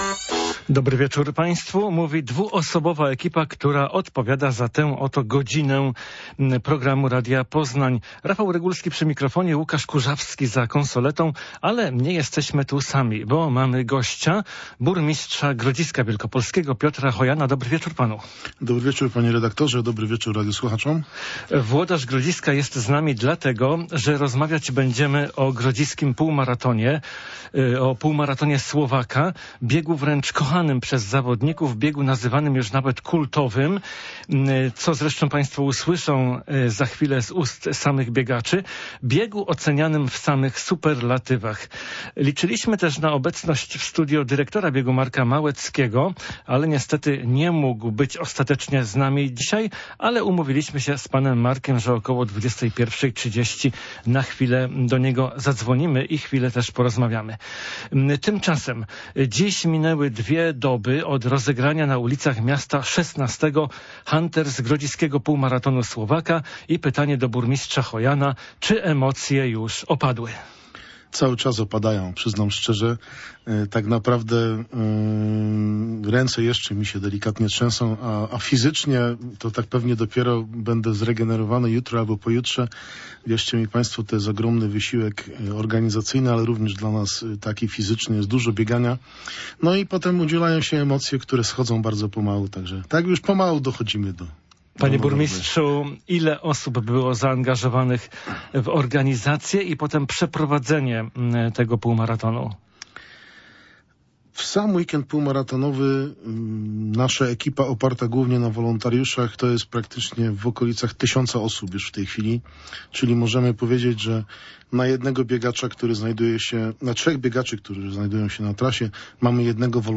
Zaproszenie do studia przyjął burmistrz Grodziska Piotr Hojan.